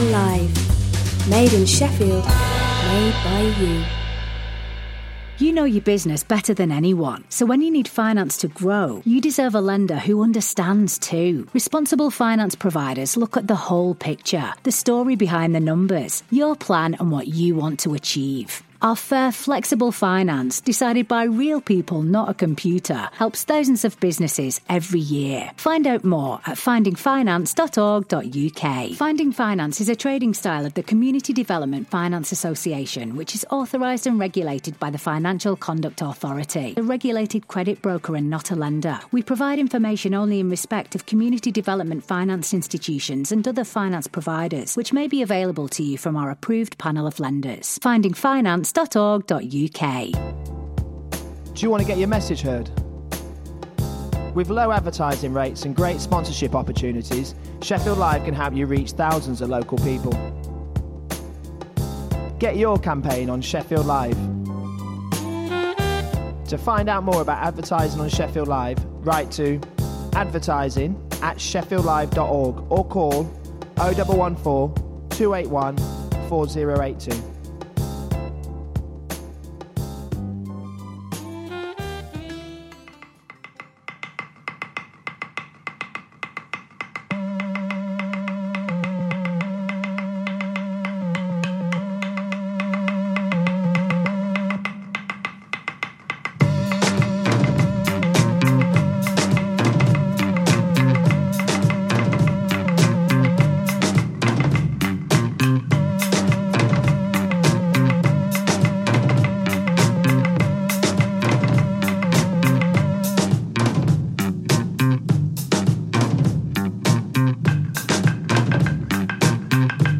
Underground, local, global, universal music and people.